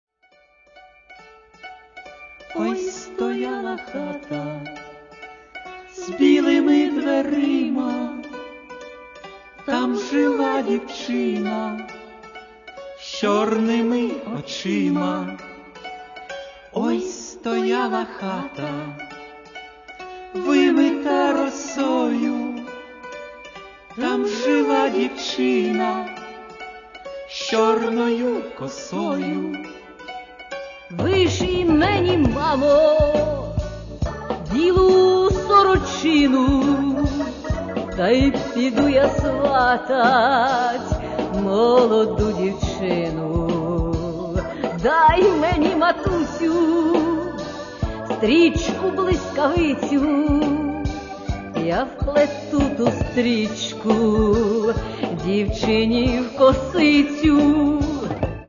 Здається, цим пісням дещо бракує тихого шурхотіння.